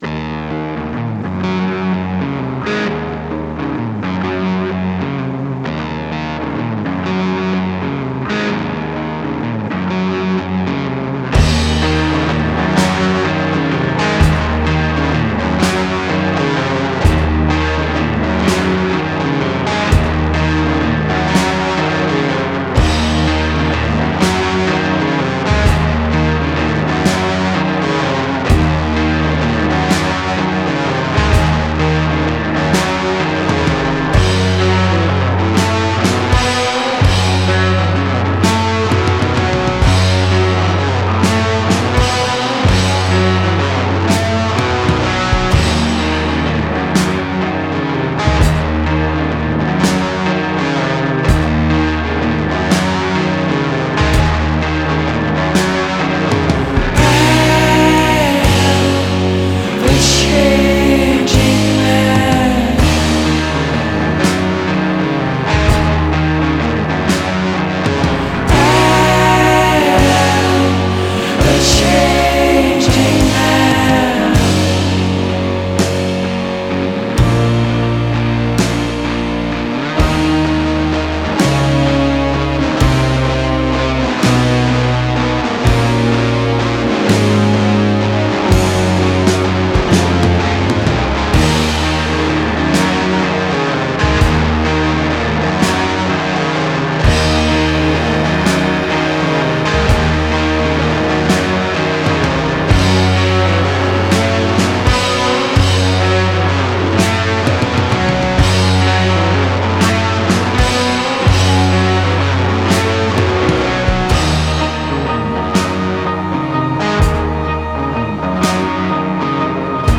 Genre: Folk Rock, Singer-Songwriter